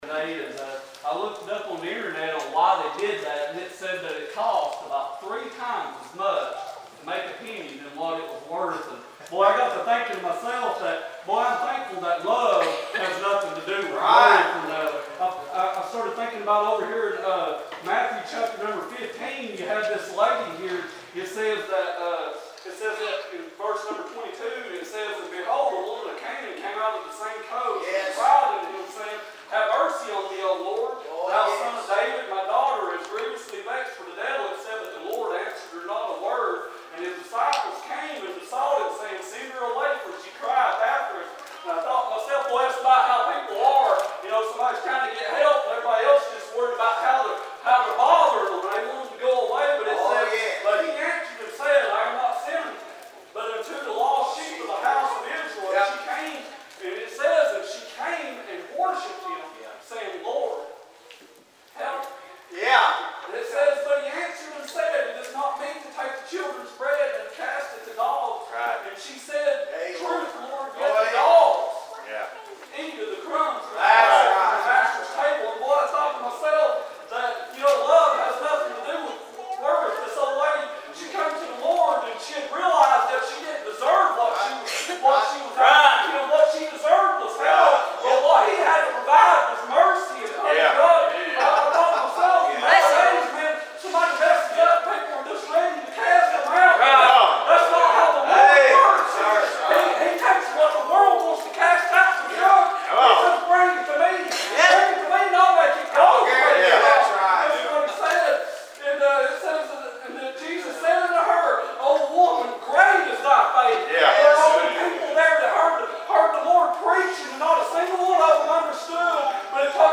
19-20 Service Type: Wednesday Evening « March 25